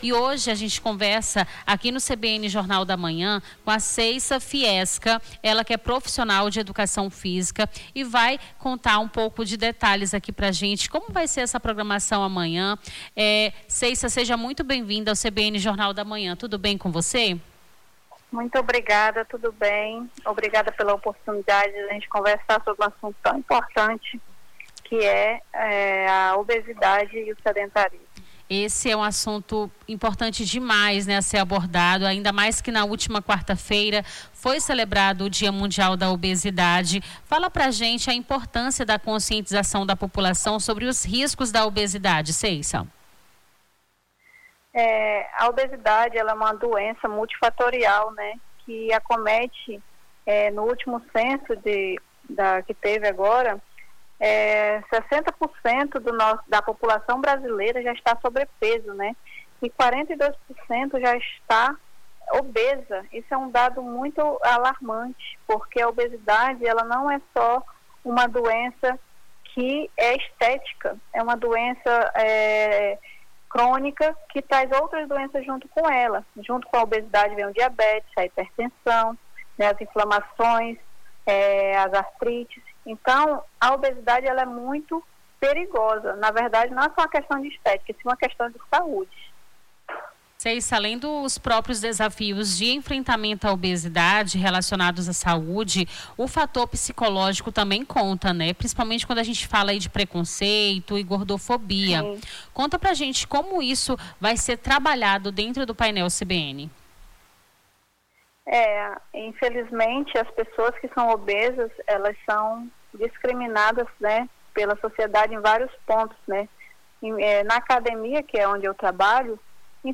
Nome do Artista - CENSURA - ENTREVISTA PAINEL CBN - 09-03-26.mp3